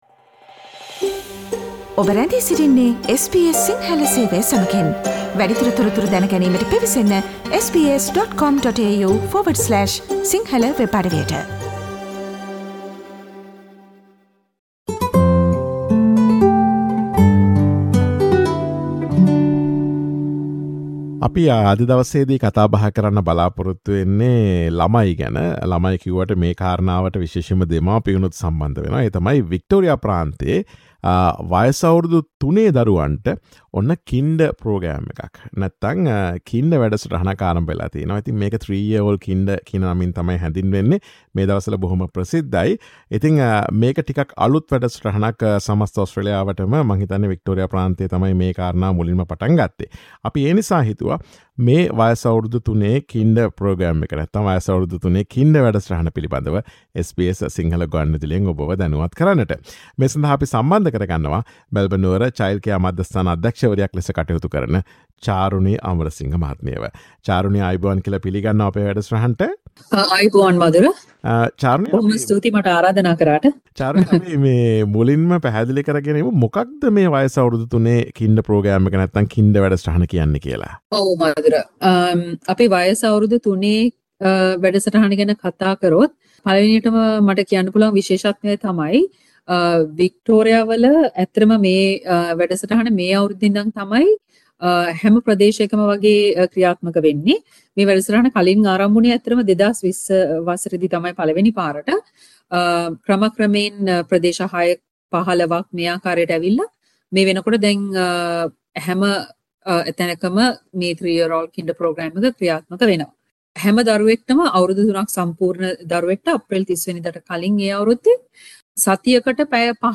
වයස අවුරදු 3 ළමුන් සඳහා ක්‍රියාත්මක වික්ටෝරියා ප්‍රාන්තයේ පෙර පාසල් වැඩසටහන පිළිබඳ SBS සිංහල ගුවන් විදුලිය සිදුකළ සාකච්ඡාවට සවන් දෙන්න.